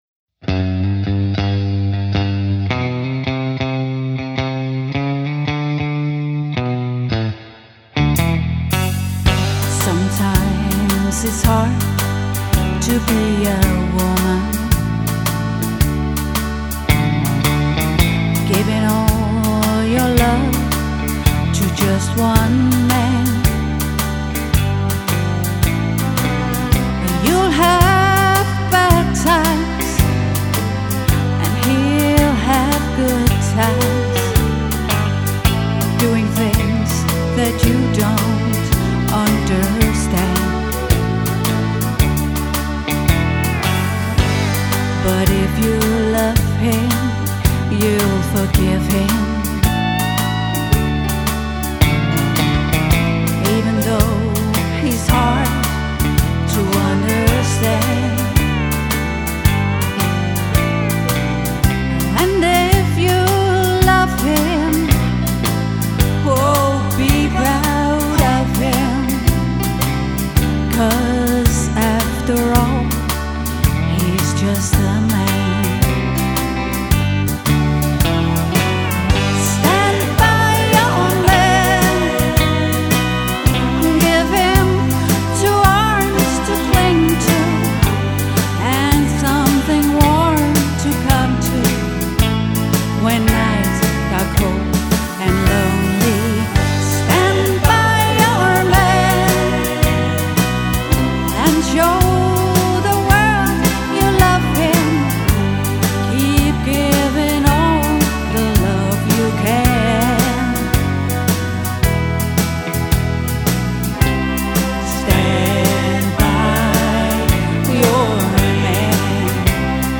guitar og vokal
keyboards og vokal
• Coverband